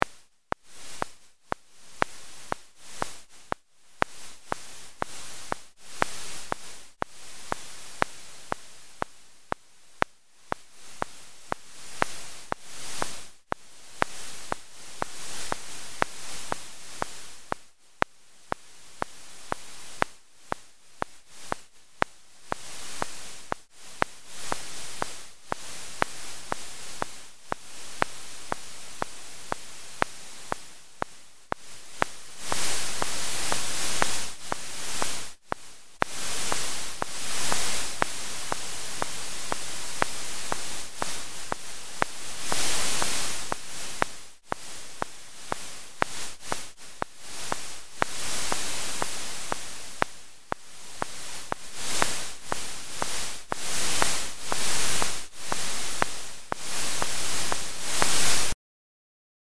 Here is the sonification for PSR 1133+16.
The period of this pulsar was 1168.4 ms. A typical Neutron star has a radius of about 12 km and a mass of 1.4 solar masses.